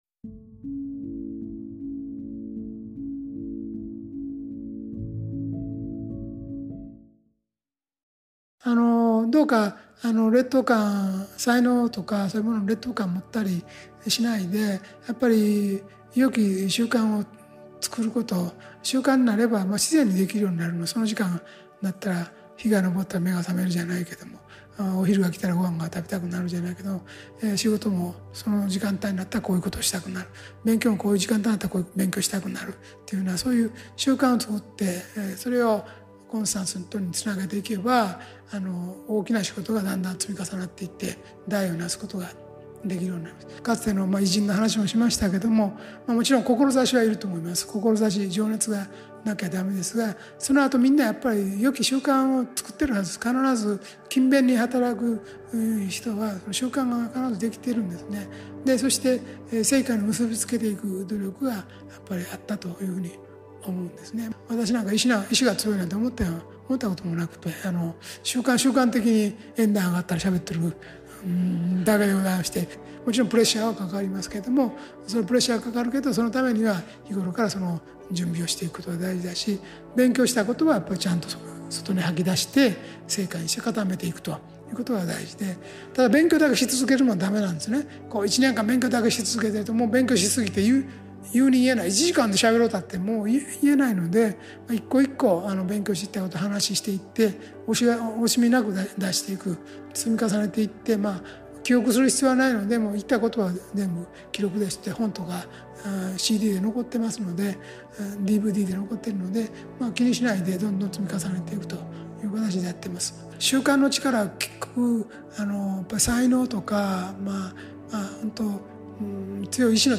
ラジオ番組「天使のモーニングコール」で過去に放送された、幸福の科学 大川隆法総裁の説法集です。
大川隆法総裁法話「天意を正しく受け取る法」質疑応答（『人格力』第1章所収）